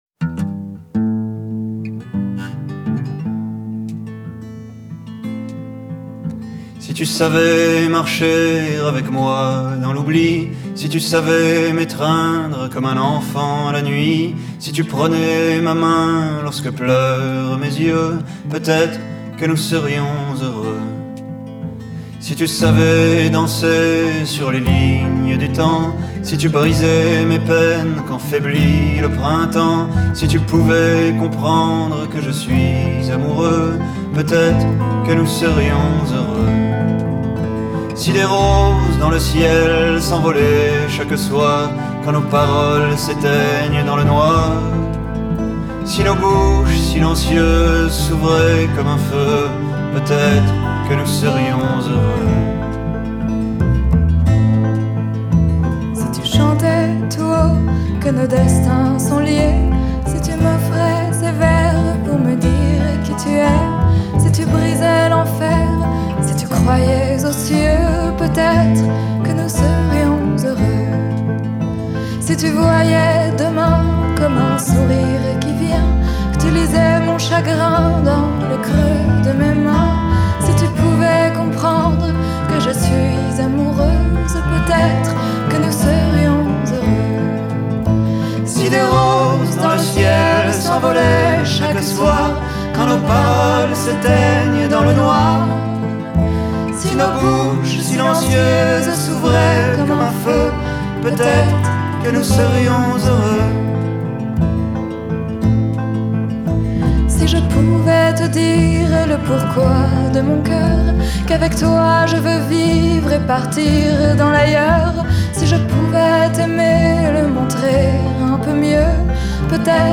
Genre: World, French Pop, Indie, Chanson